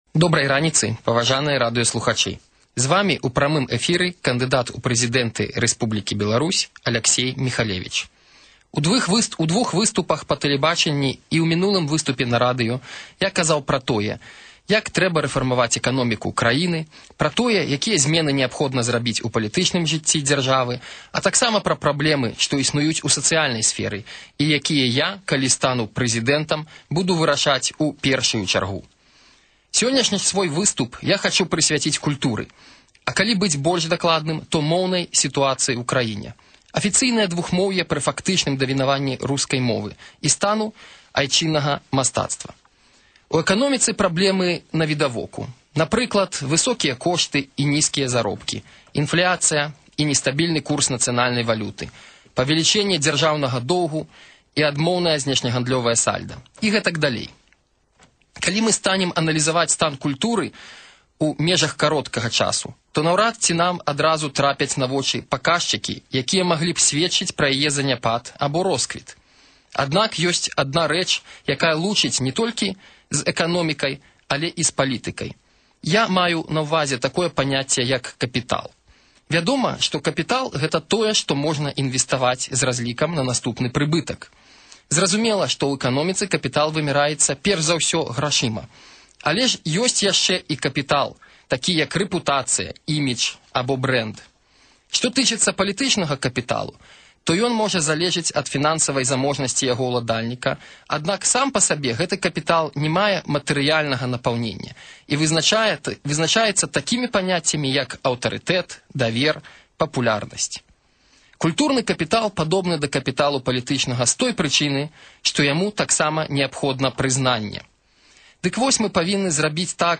Другі радыёзварот Алеся Міхалевіча.